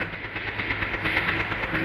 Index of /musicradar/rhythmic-inspiration-samples/130bpm